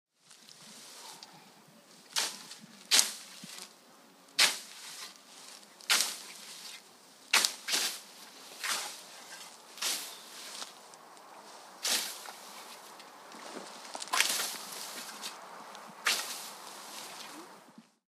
На этой странице собраны звуки косы — от металлического звона лезвия до ритмичного шелеста скошенной травы.
Еще один способ применения косы для скашивания травы